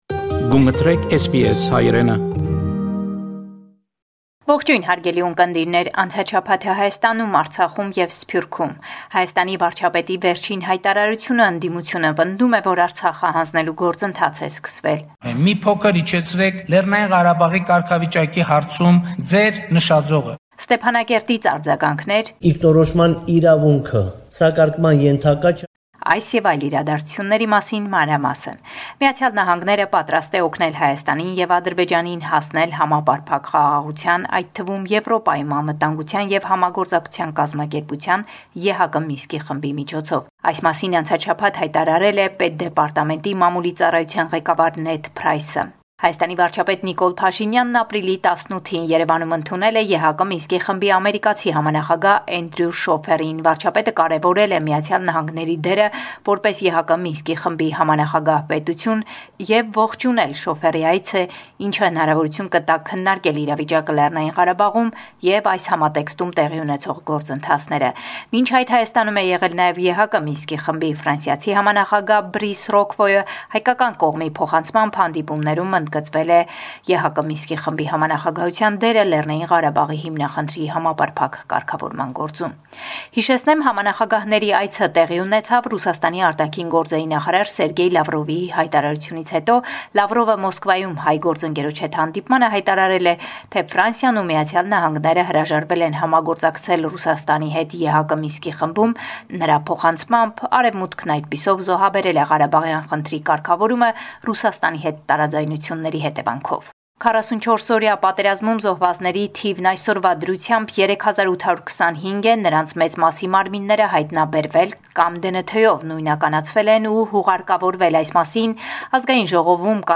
Վերջին Լուրերը Հայաստանէն – 19 Ապրիլ, 2022